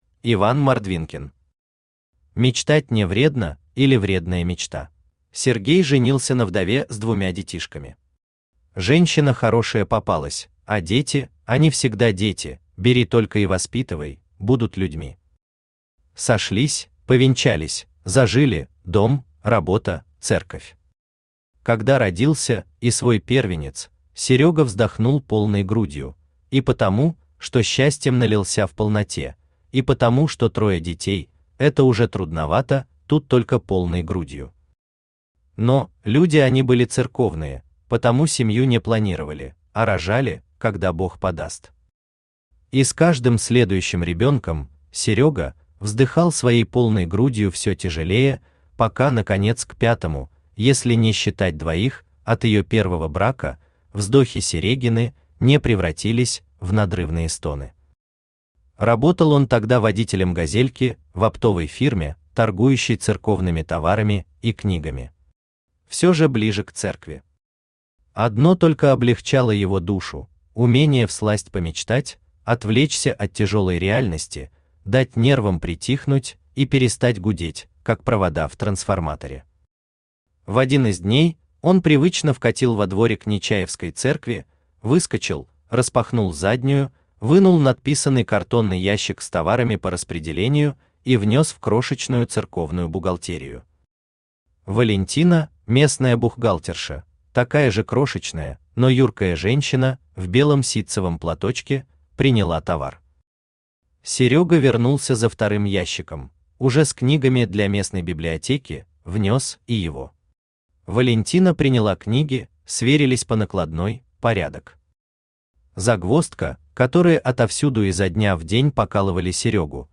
Аудиокнига Мечтать не вредно, или Вредная мечта | Библиотека аудиокниг